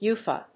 ウエファ